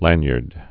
(lănyərd)